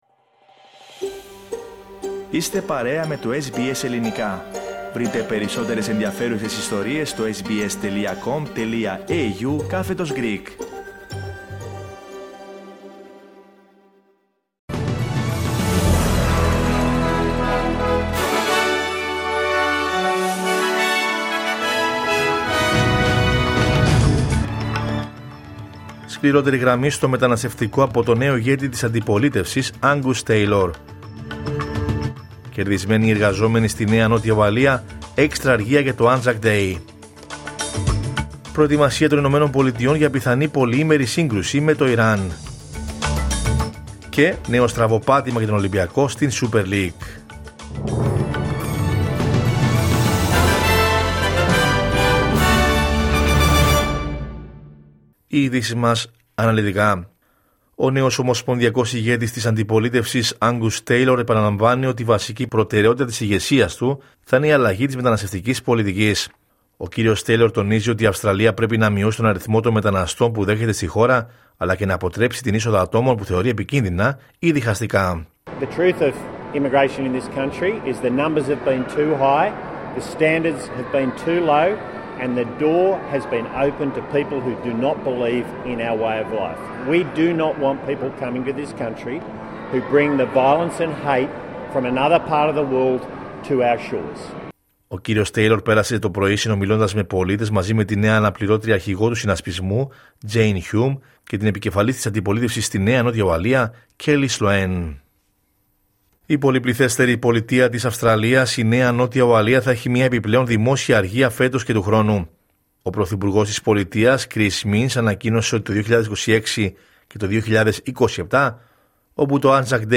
Ειδήσεις: Κυριακή 15 Φεβρουαρίου 2026